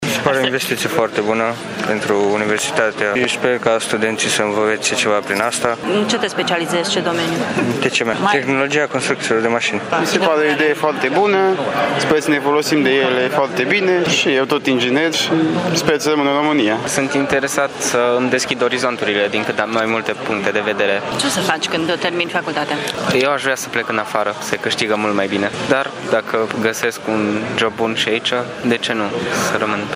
Studenții târgumureșeni, inginerii de mâine, sunt nerăbdători să învețe în noile laboratoare însă nu sunt siguri că vor rămâne în România după facultate: